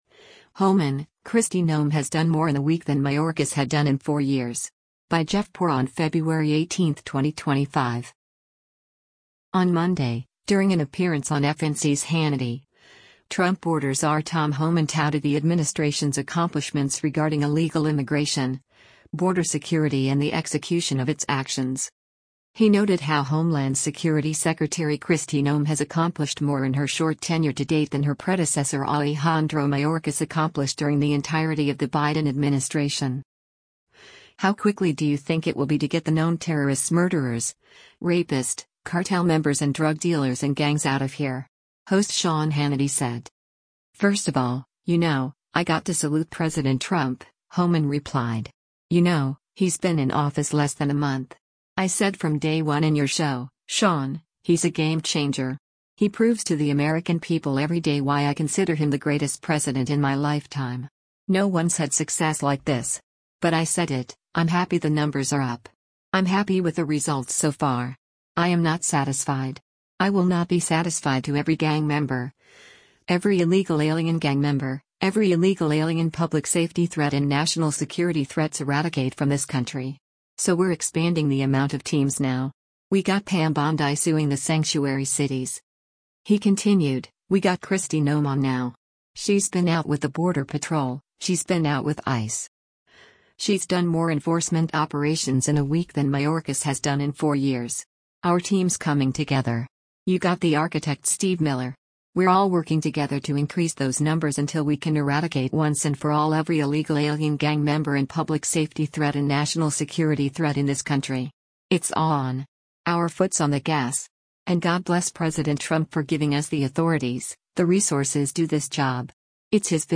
On Monday, during an appearance on FNC’s “Hannity,” Trump border czar Tom Homan touted the administration’s accomplishments regarding illegal immigration, border security and the execution of its actions.